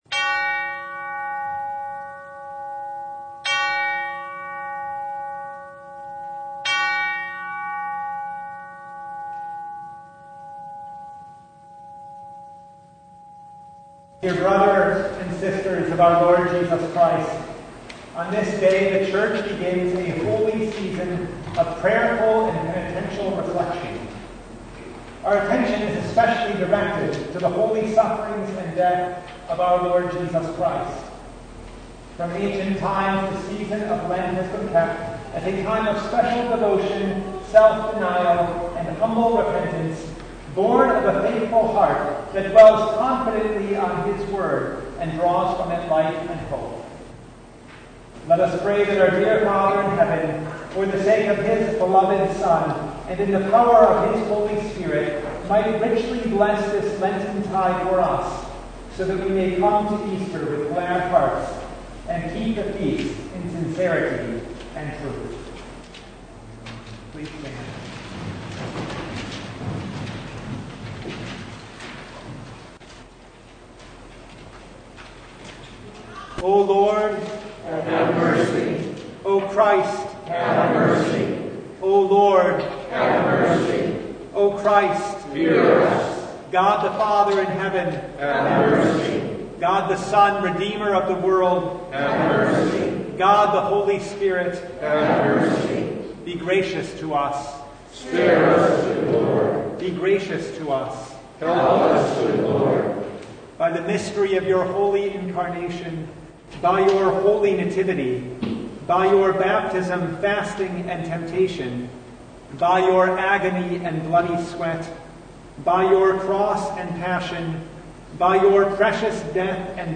Download Files Notes Bulletin Topics: Full Service « Close to God Return »